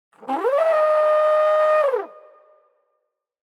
UI_Point_Elephant.ogg